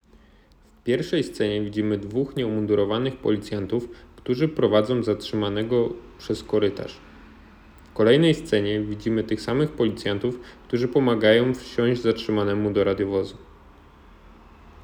Nagranie audio audiodeksrypcja